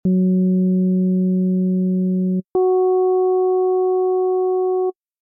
When you get down to about 55 you should hear that the sound generated by this Subtractor starts to sound like a part of the overall tone rather than a note in its own right.